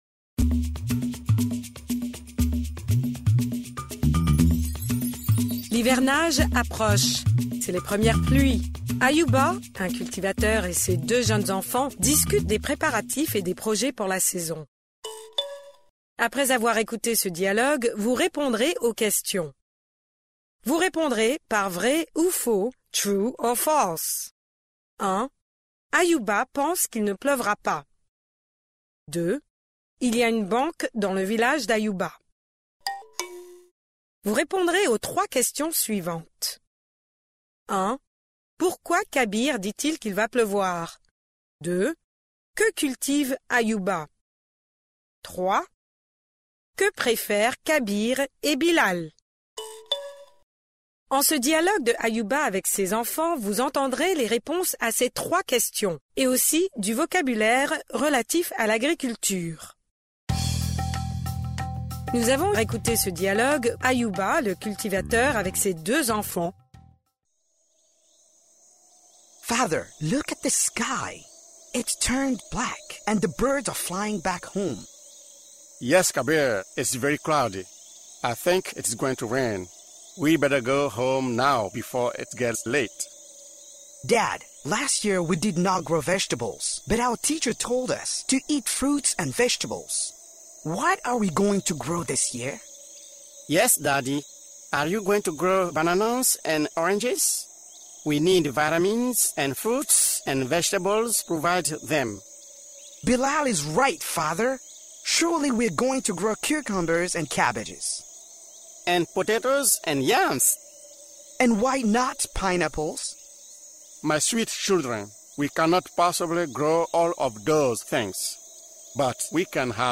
L’hivernage approche. C’est les premières pluies. Ayouba (un cultivateur) et ses deux jeunes enfants discutent des préparatifs et des projets pour la saison.